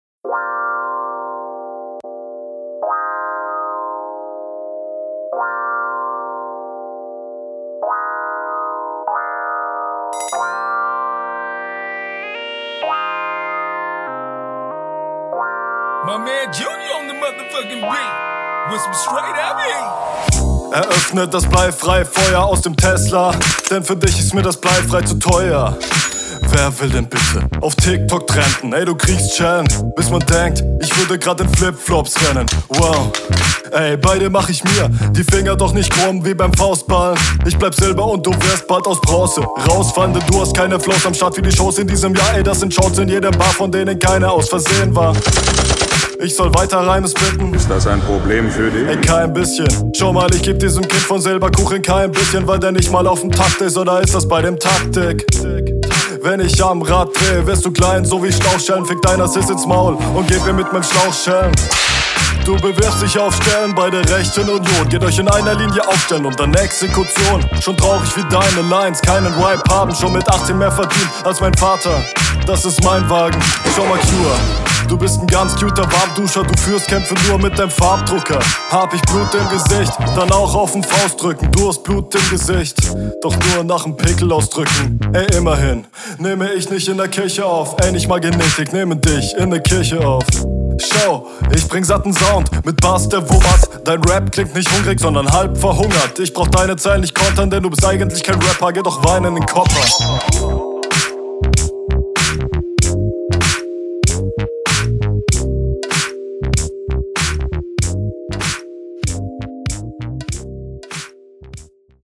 klingt komisch irwie auch in rr1 kp bin auch kein audio engineer und habe damit …